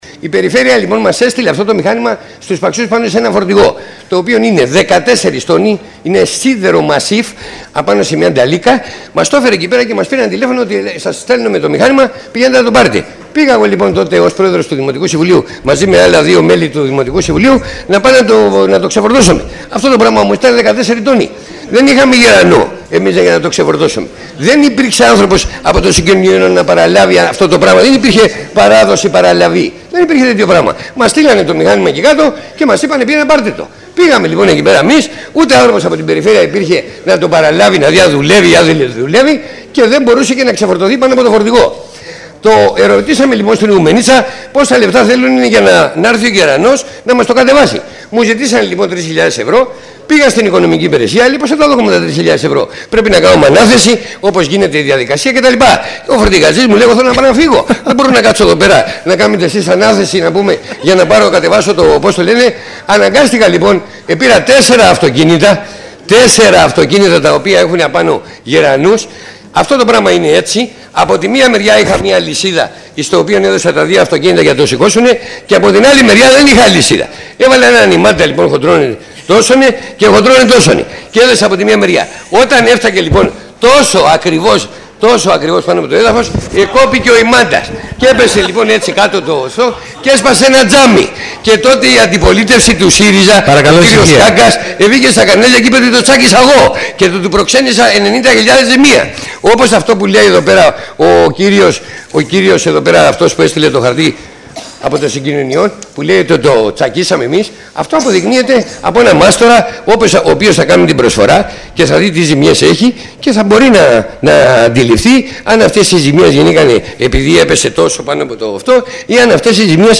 Στη συνέχεια παραθέτουμε αποσπάσματα από την ομιλία της αντιπεριφερειάρχη Μελίτας Ανδριώτη και του Αντιδημάρχου Παξών Μανώλη Βλαχόπουλου: